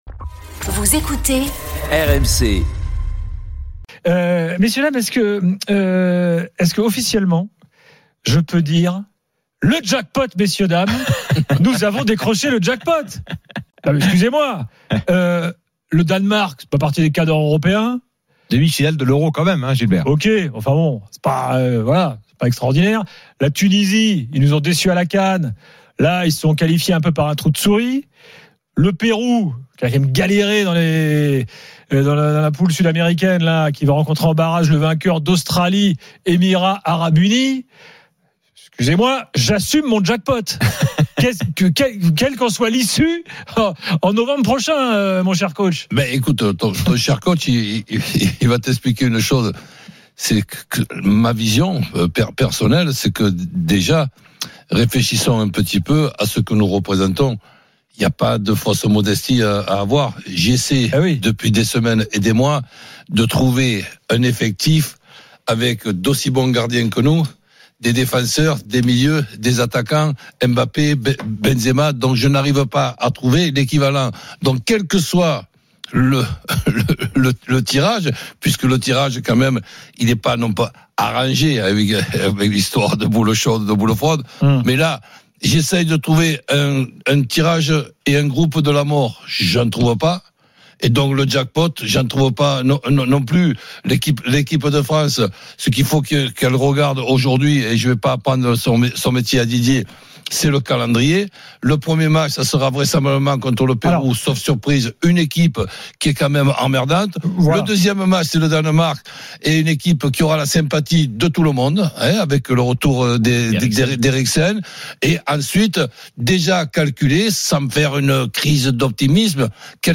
Chaque jour, écoutez le Best-of de l'Afterfoot, sur RMC la radio du Sport !
RMC est une radio généraliste, essentiellement axée sur l'actualité et sur l'interactivité avec les auditeurs, dans un format 100% parlé, inédit en France.